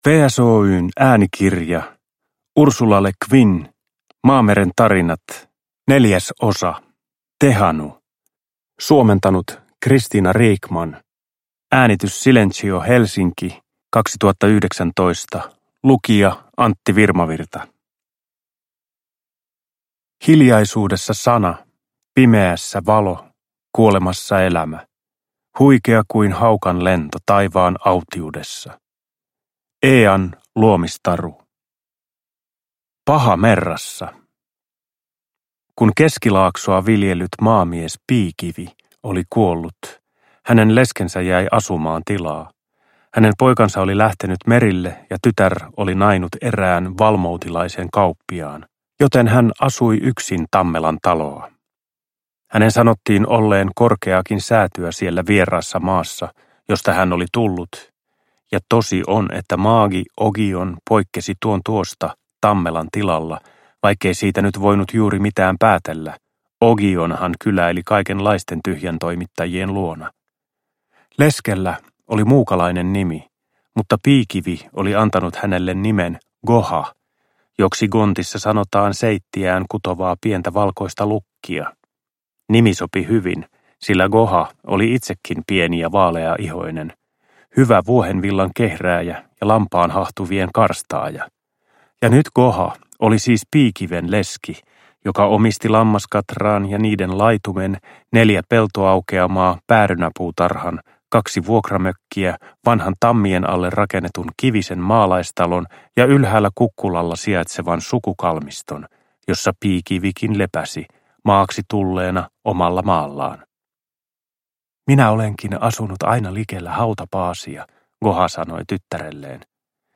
Tehanu (ljudbok) av Ursula K. Le Guin